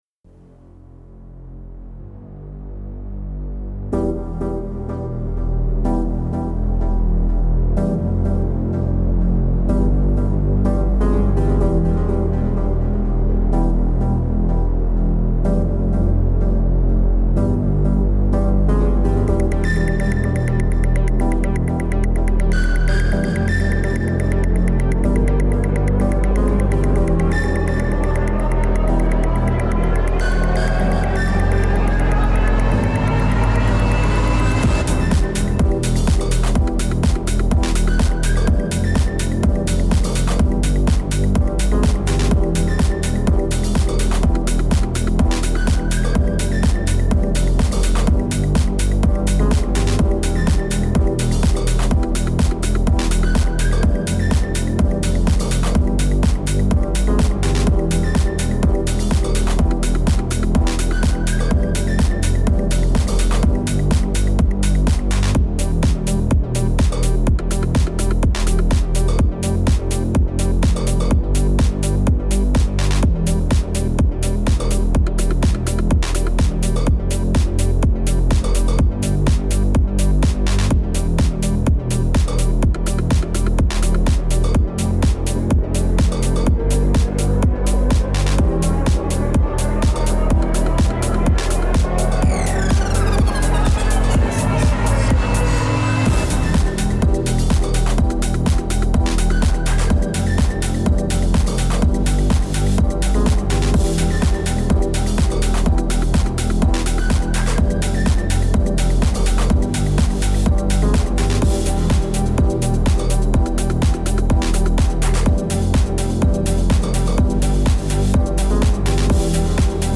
is a very typical 90s sounding trance based track.
Tempo 125BPM (Allegro)
Genre Trance
Type INstrumental
Mood energetic